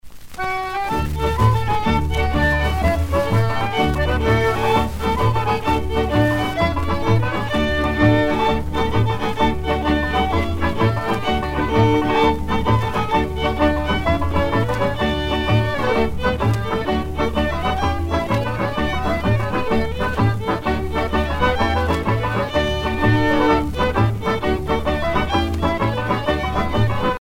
square dance